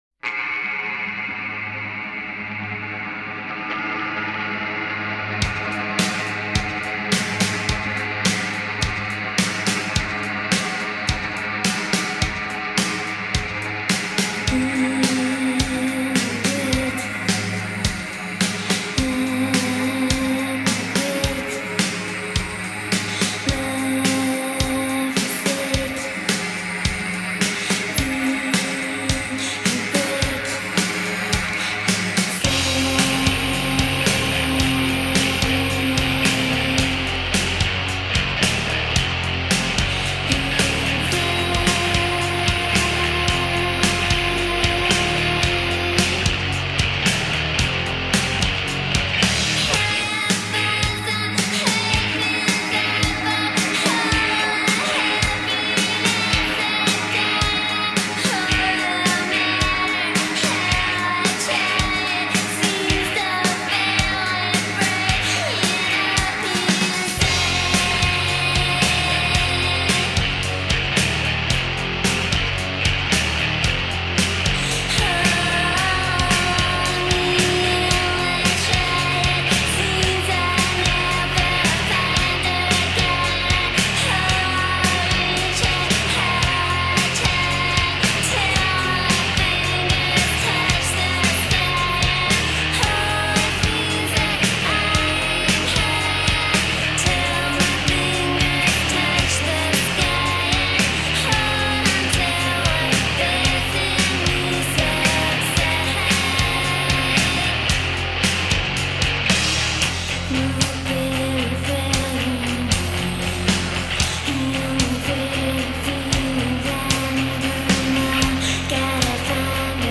epitomized Gothic Minimalism, but really favored Dream Pop.